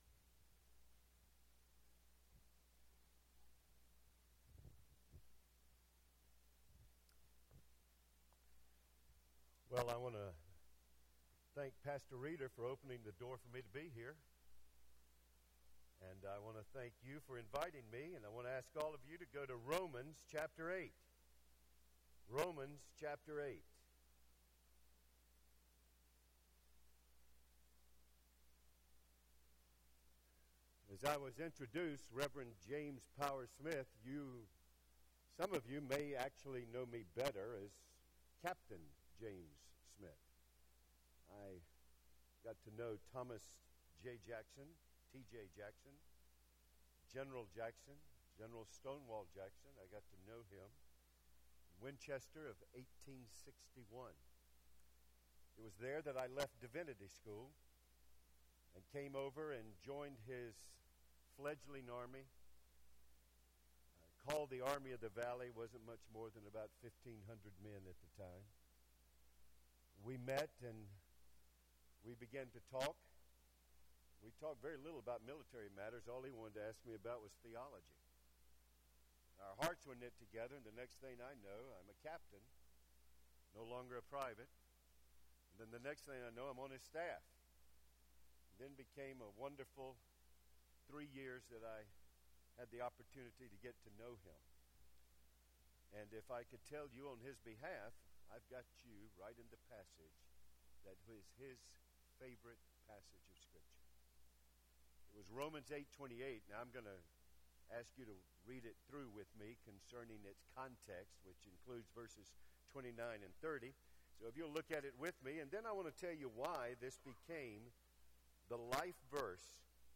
Guest Preacher
Sermon